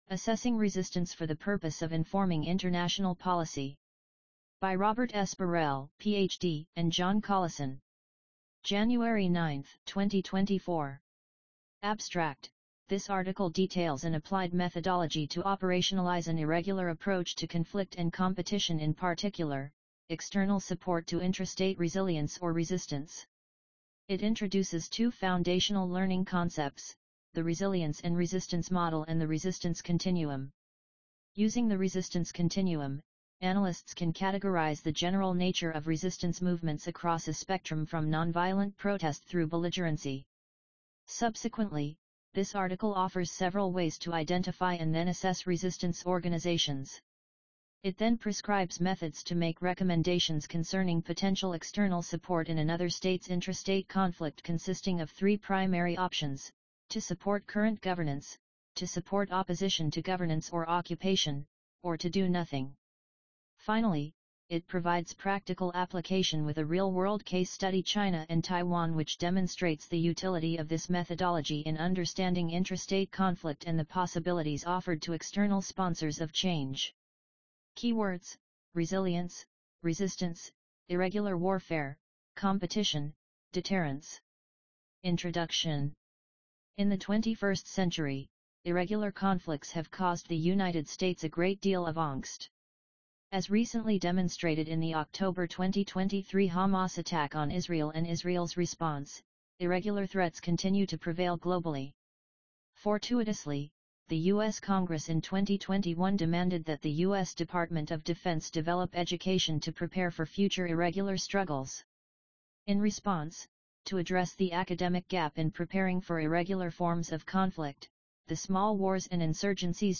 EXP_Burrell_Collison_Assessing Resistance_AUDIOBOOK.mp3